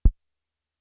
Index of /90_sSampleCDs/300 Drum Machines/Fricke Schlagzwerg/Kicks
Kick.WAV